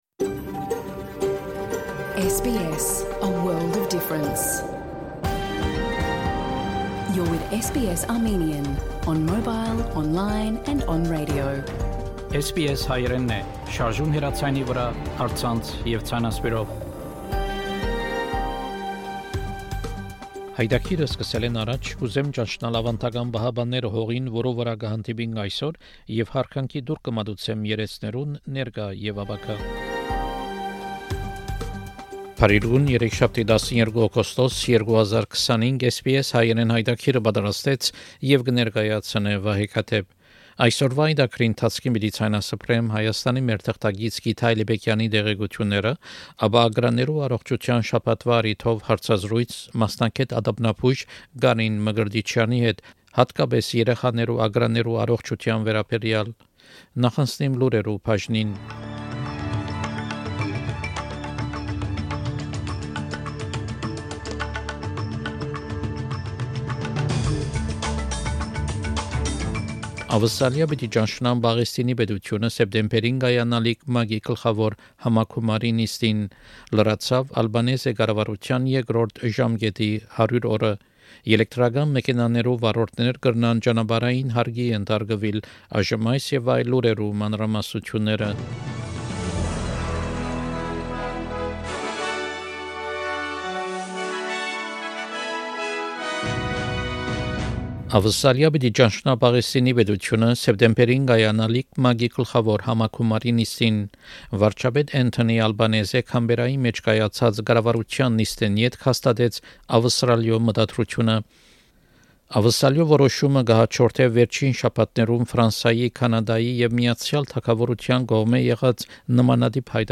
SBS Armenian news bulletin from 12 August 2025 program. Main Headlines • Australia will recognize the state of Palestine at the UN General Assembly in September.